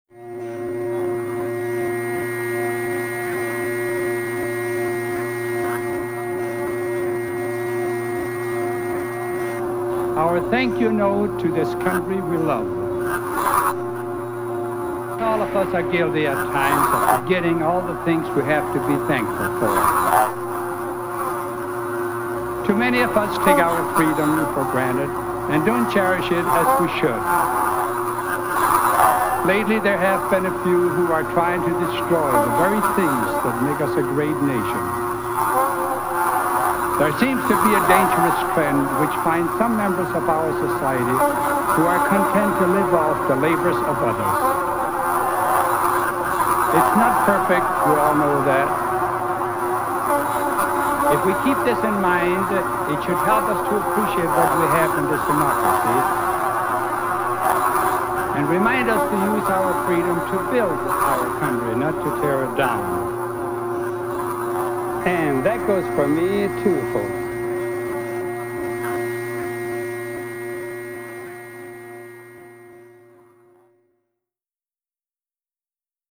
Collage of Celebrity Speech
This is a gallery of collage derived from the utterings of media celebrities, created by artists from around the planet.
Creator: Big City Orchestra